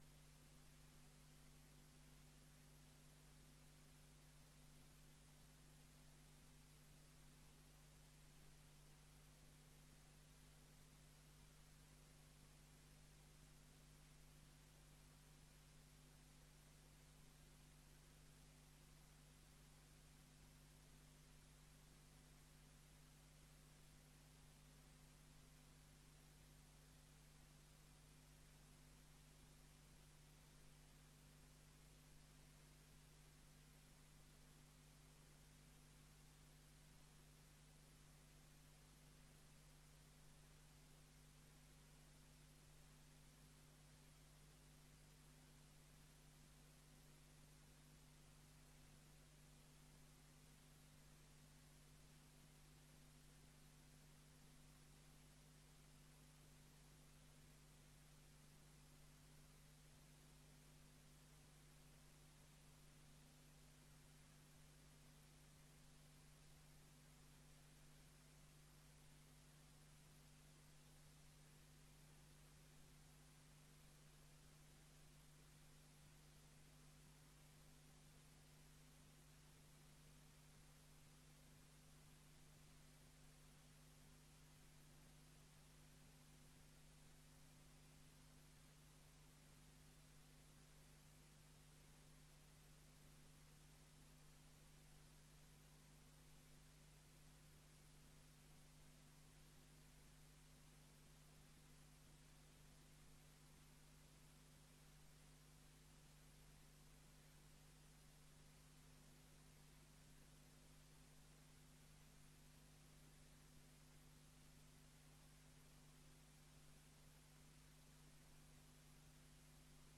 Download de volledige audio van deze vergadering
Locatie: Raadzaal